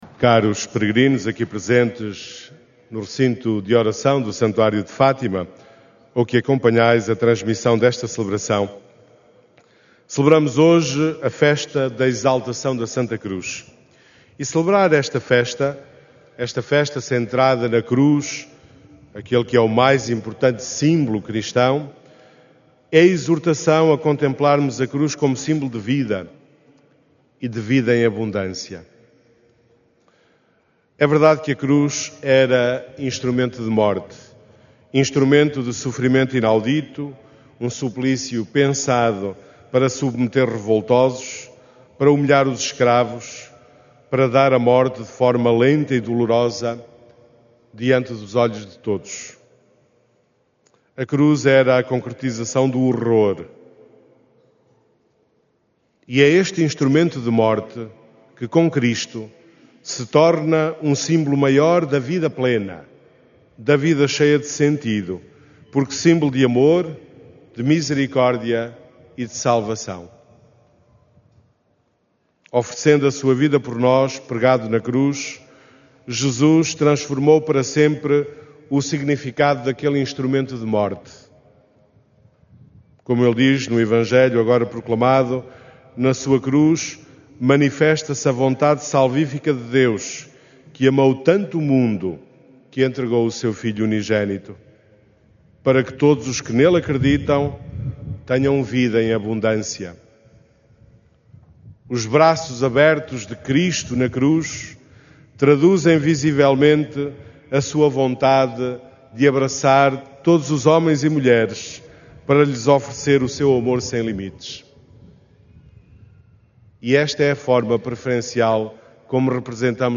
Na missa deste domingo, no Santuário de Fátima, os peregrinos celebraram a Festa da Exaltação da Santa Cruz, “o mais importante símbolo cristão”.
Áudio da homilia